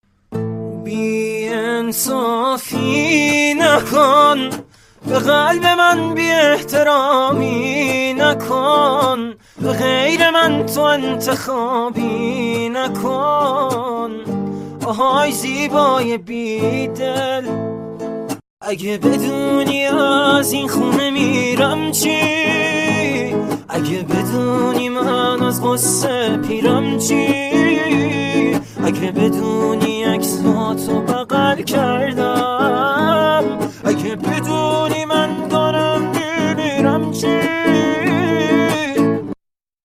با گیتار